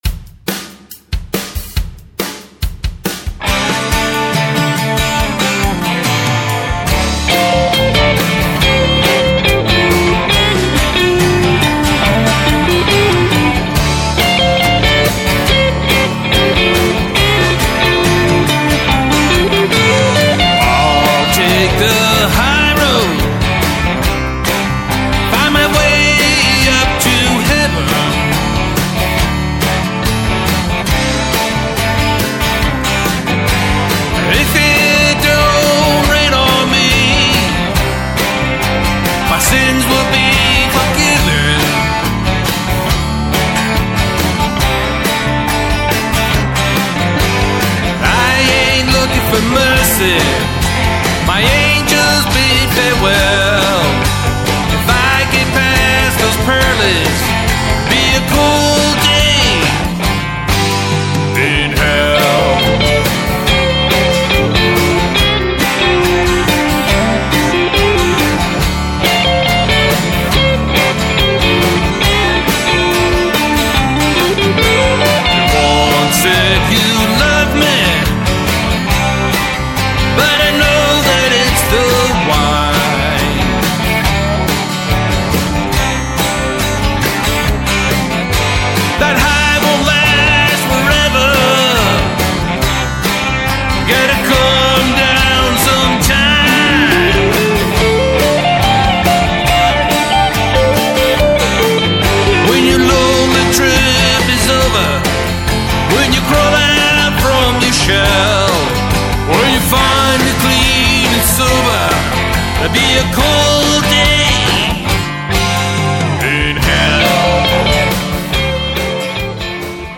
A simple rocker.   4